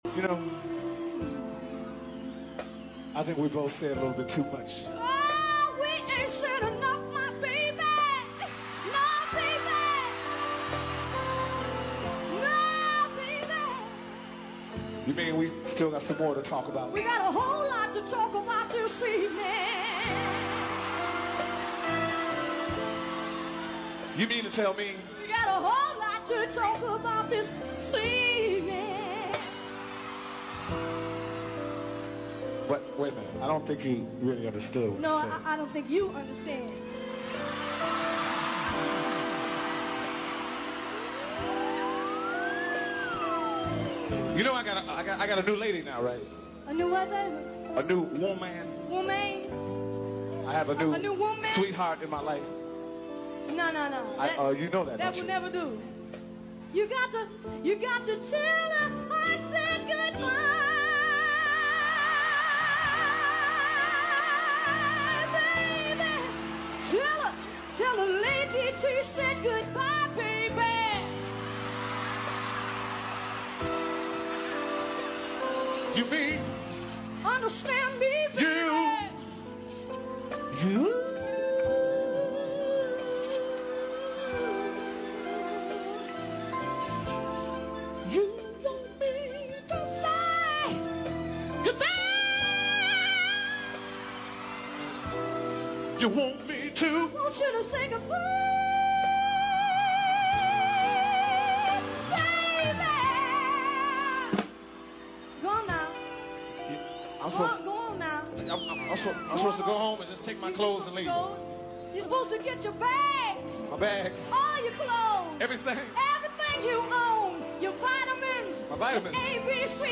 In Concert.